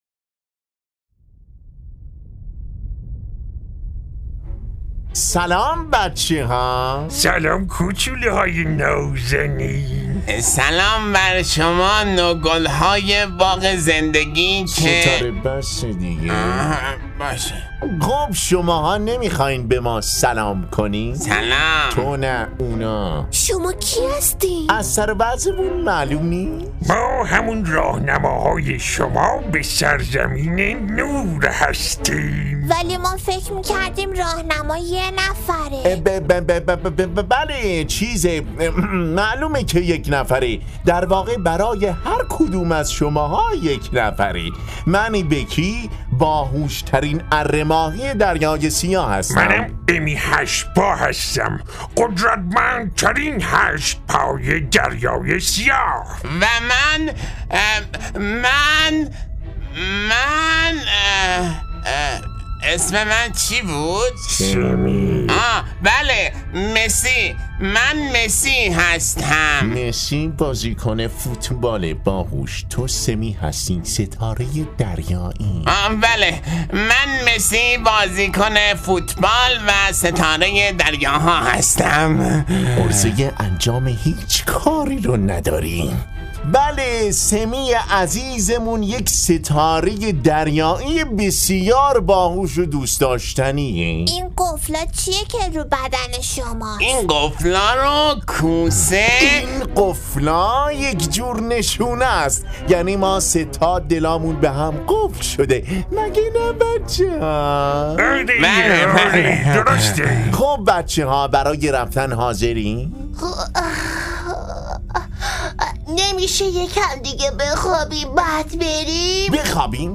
نمایشنامه صوتی در جستجوی نور - قسمت دوم - متفرقه با ترافیک رایگان
قسمت دومدر این نمایشنامه صوتی در جست و جوی نور، مجموعه ای کودکانه به مناسبت عید غدیر خم را خواهید شنید .